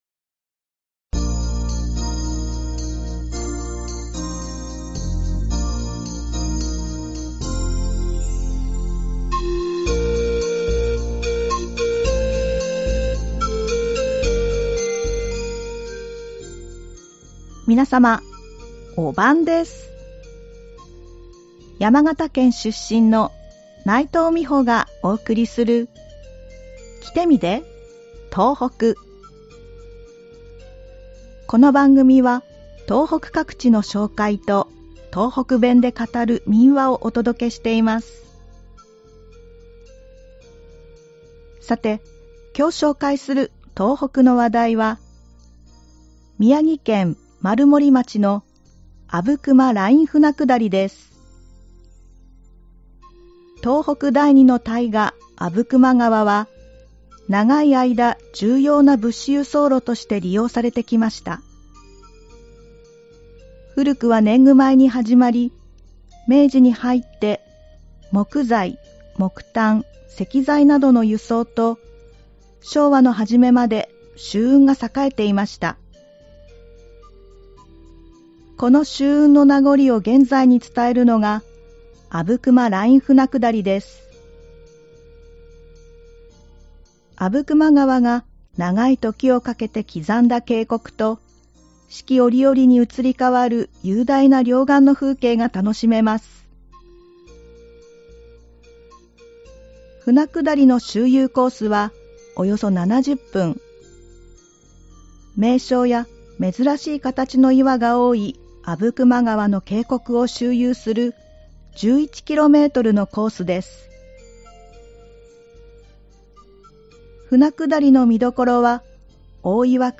この番組は東北各地の紹介と、東北弁で語る民話をお届けしています。さて、今日紹介する東北の話題は、宮城県丸森町の阿武隈ライン舟下りです。